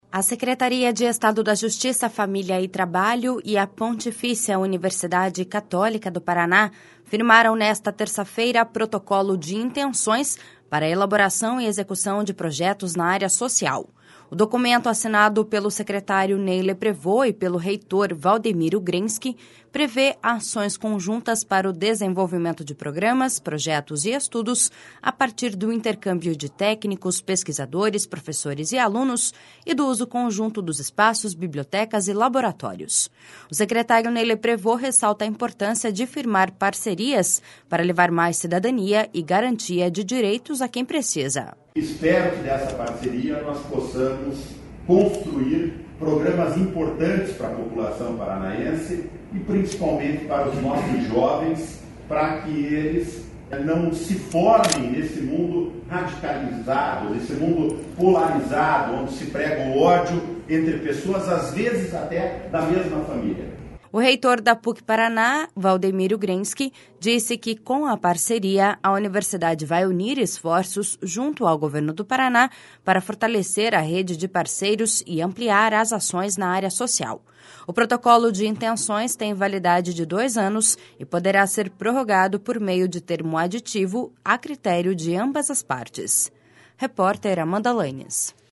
O secretário Ney Leprevost ressalta a importância de firmar parcerias para levar mais cidadania e garantia de direitos a quem precisa.// SONORA NEY LEPREVOST.//